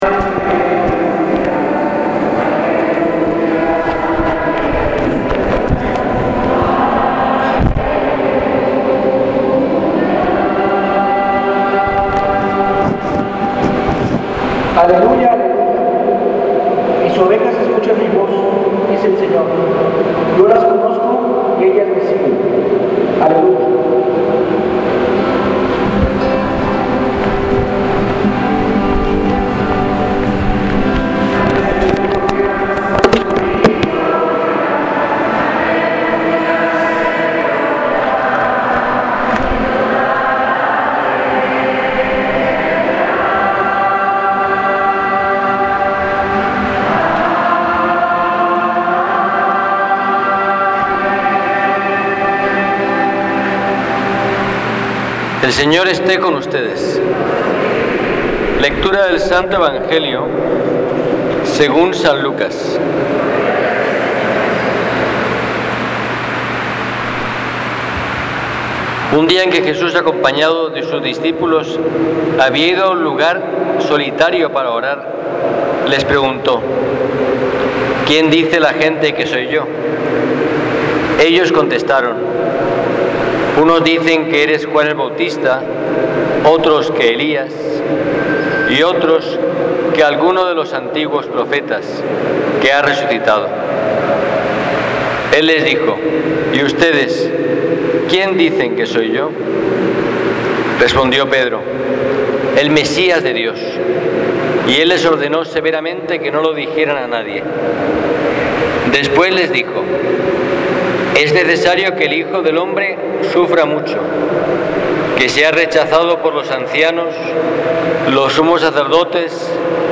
Publicado en Homilias